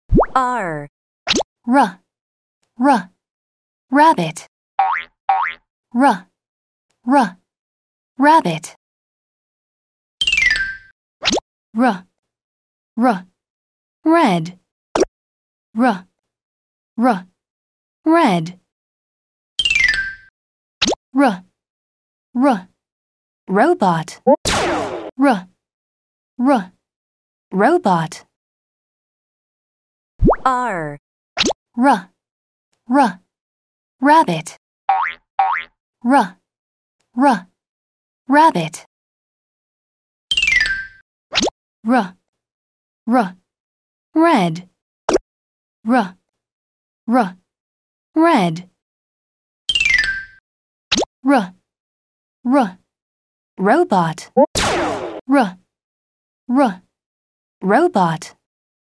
Alphabet song